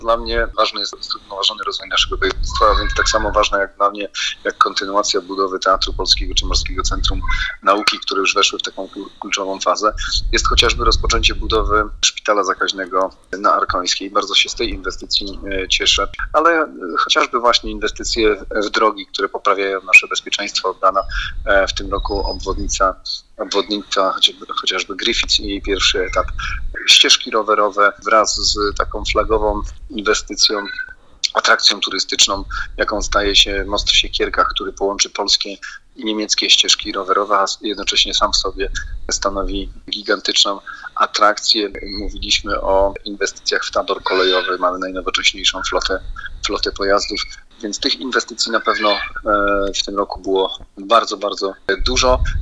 Jest to ciągły proces – mówi Olgierd Geblewicz, Marszałek Województwa Zachodniopomorskiego.